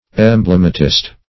Search Result for " emblematist" : The Collaborative International Dictionary of English v.0.48: Emblematist \Em*blem"a*tist\, n. A writer or inventor of emblems.